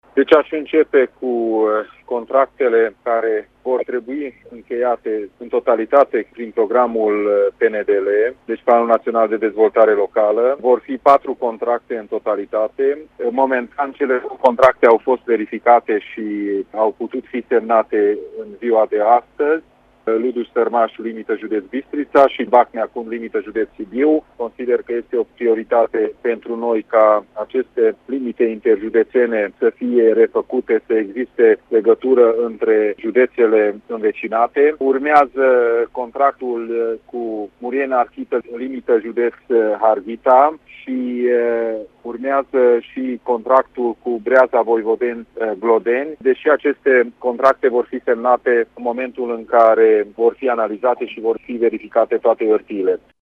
Din partea administrației Mureșene, a semnat aceste contracte Peter Ferenc, președintele Consiliului Județean: